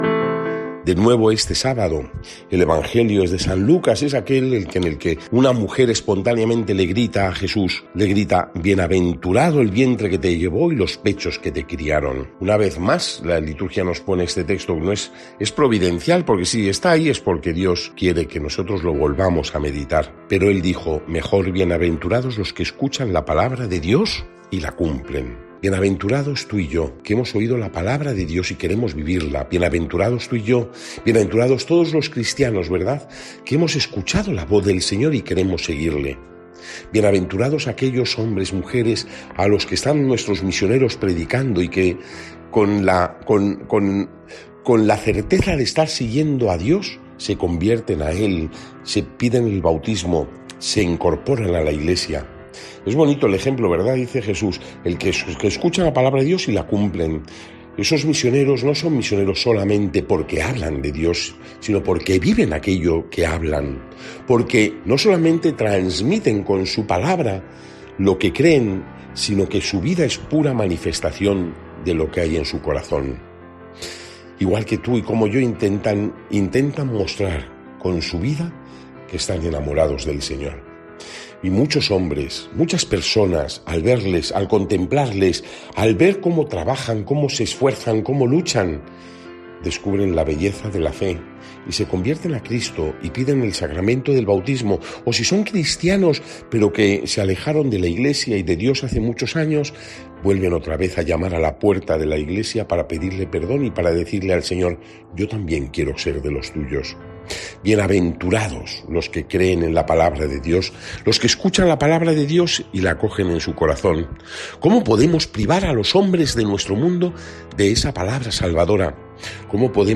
Lectura del santo Evangelio según san Lucas 11,27-28